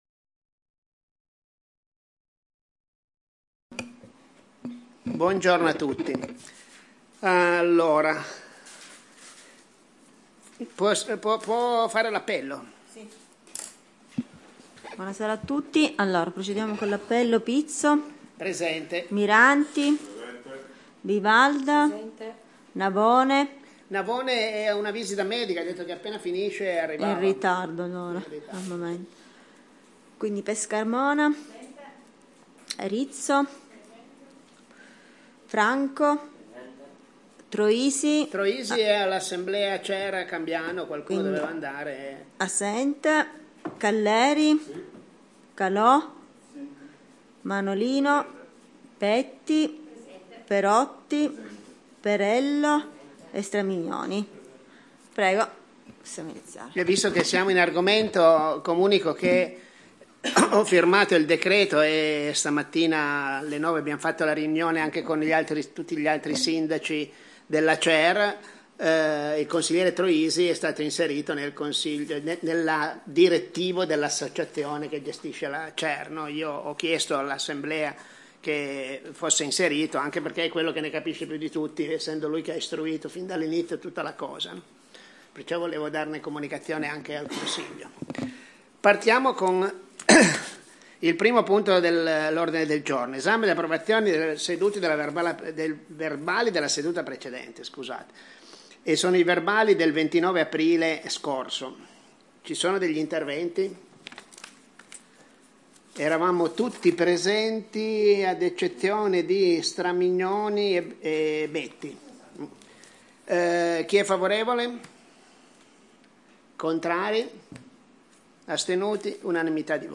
Registrazione Consiglio comunale - Commune de Pecetto Torinese